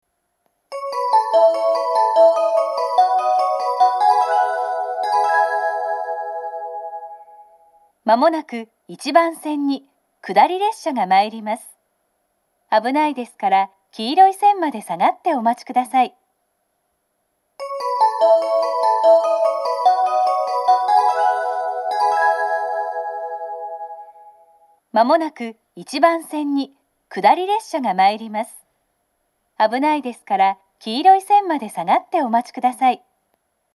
１番線接近放送 下り本線です。